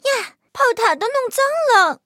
M2中坦小破语音1.OGG